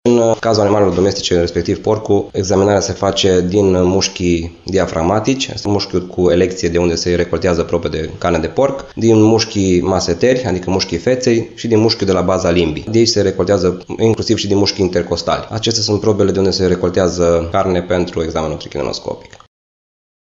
medicul veterinar